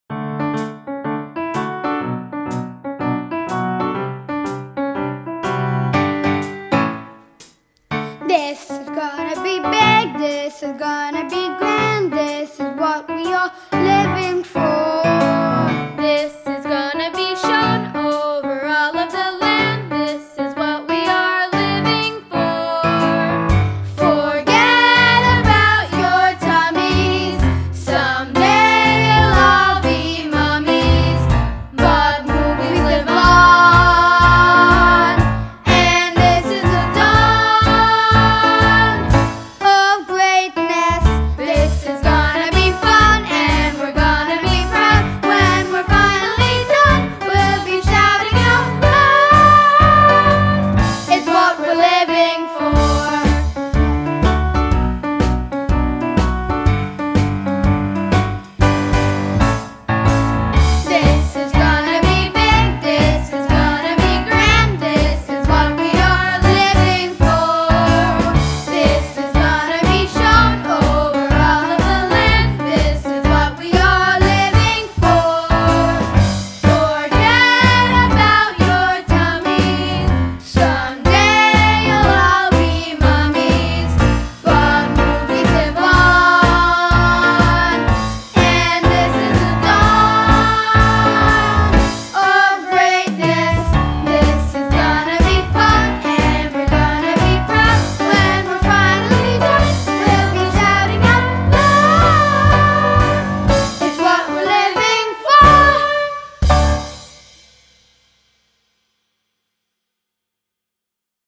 Vocal: Garden Player Kids